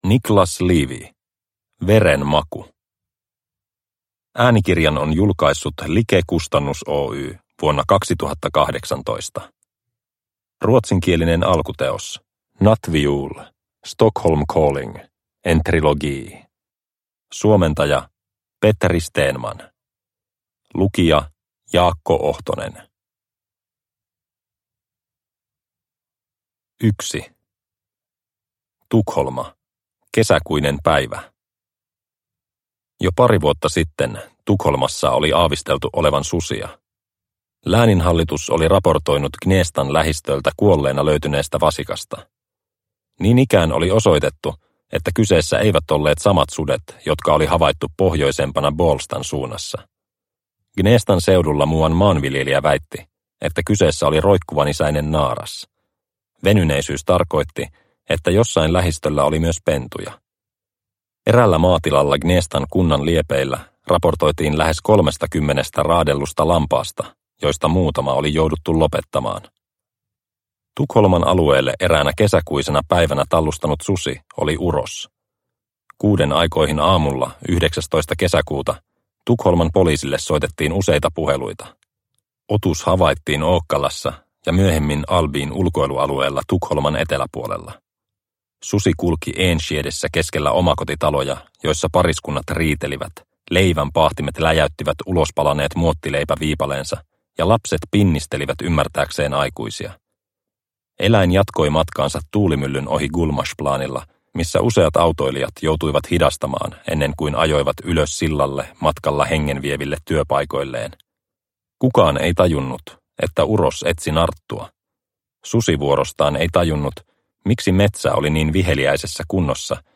Veren maku – Ljudbok – Laddas ner